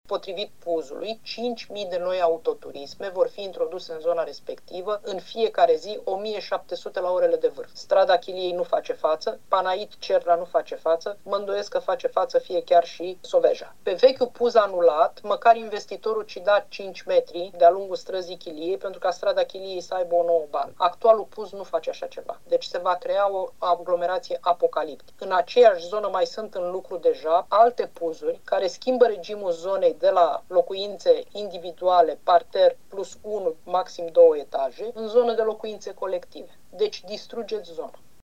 Proiectul care prevederea construirea unui ansamblu rezidențial cu mii de locuințe pe terenul fostei baze RATC din Constanța a fost respins, din nou vineri, prin vot majoritar, în ședința de Consiliu Local.
Consilierul local independent Felicia Ovanesian a criticat proiectul, susținând că ar duce la blocarea circulației pe străzile din zonă: